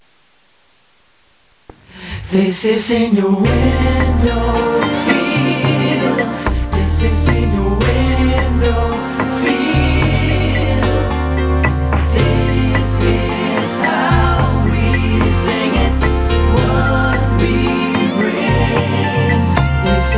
get ready to be hypnotized by their excellent vocals